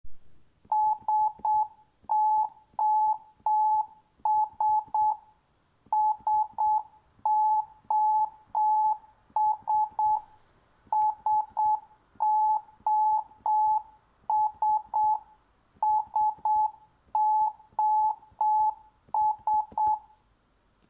SOS en morse
SOS_Morse.mp3